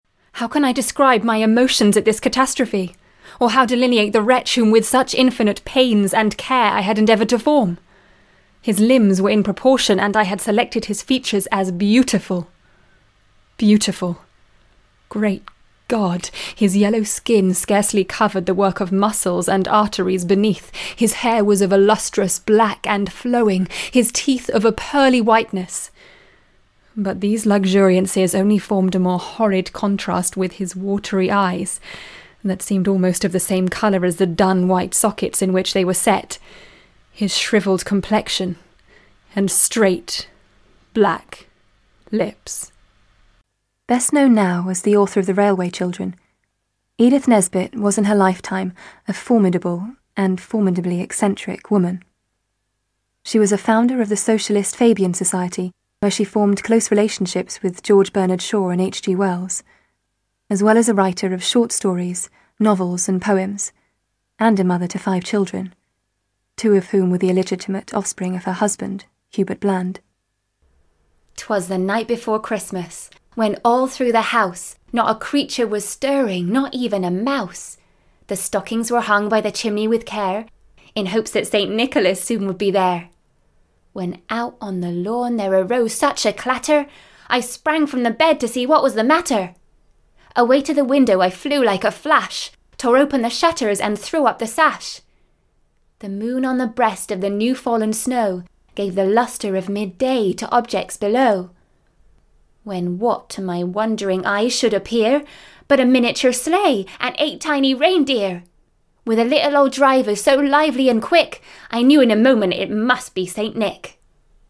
• Native Accent: Bristol, RP, Somerset, West Country
• Home Studio